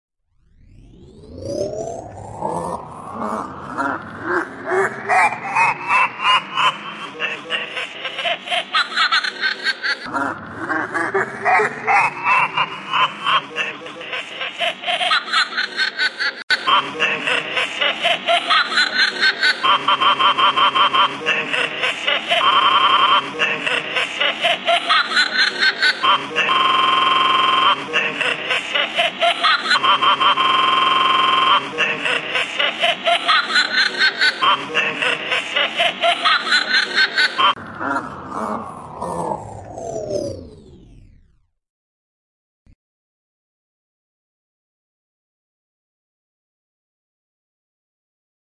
恐怖的娃娃
Tag: 娃娃 令人毛骨悚然 邪恶 恐怖